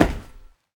scpcb-godot/SFX/Step/RunMetal2.ogg at 8f5d2fcf9fe621baf3dc75e4253f63b56f8fd64b
RunMetal2.ogg